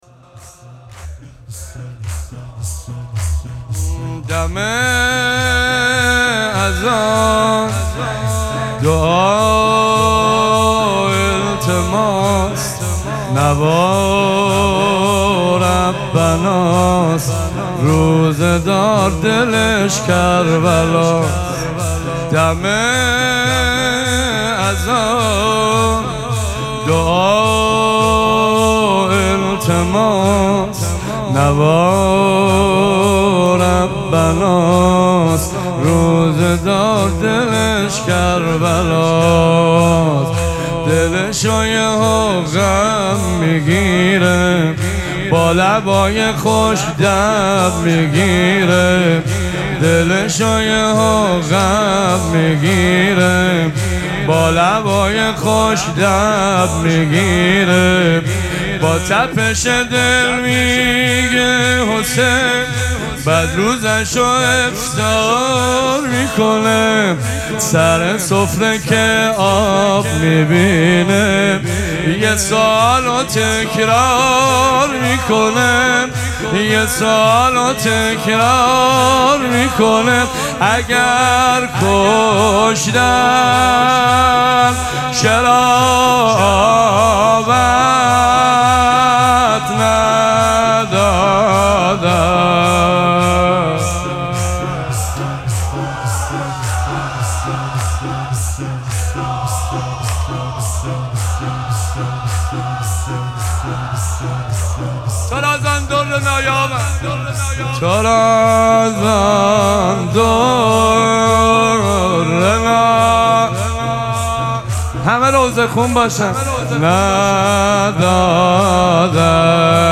مراسم مناجات شب چهاردهم ماه مبارک رمضان
حسینیه ریحانه الحسین سلام الله علیها